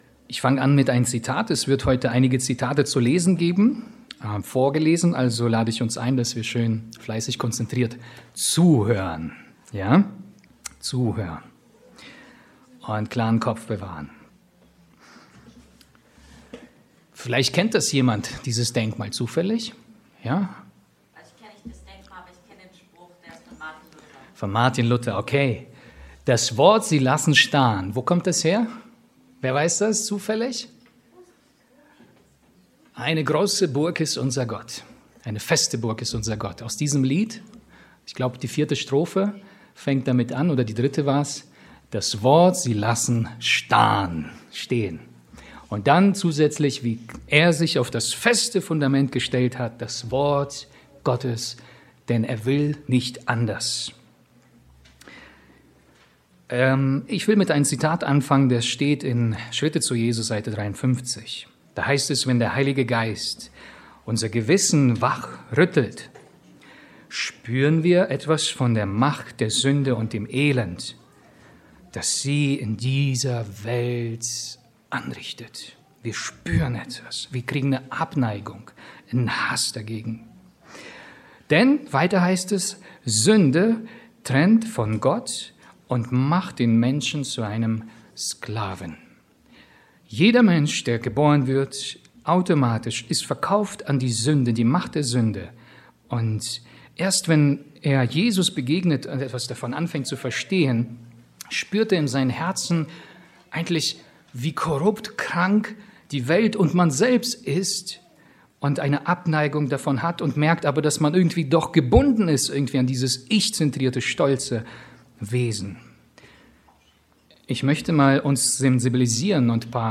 Diesen Beitrag können Sie sich auch im “Player” in voller Länge als Predigt anhören.